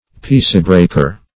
Search Result for " peacebreaker" : The Collaborative International Dictionary of English v.0.48: Peacebreaker \Peace"break`er\, n. One who disturbs the public peace.